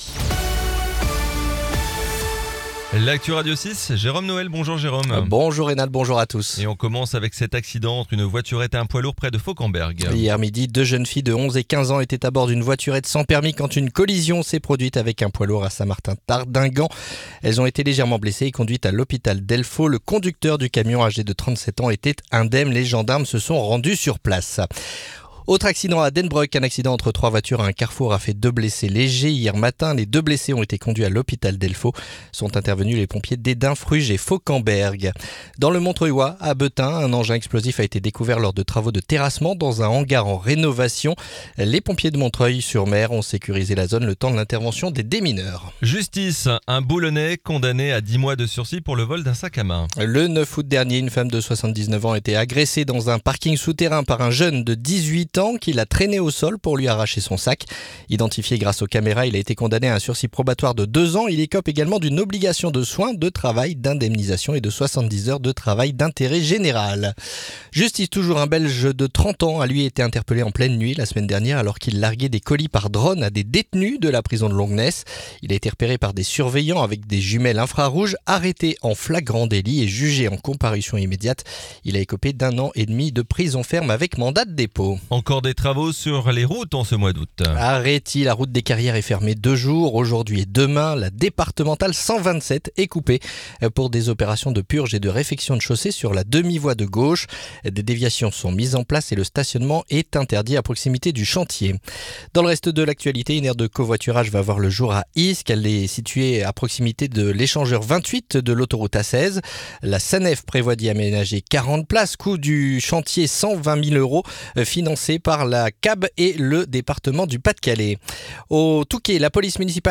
Le journal du mardi 19 août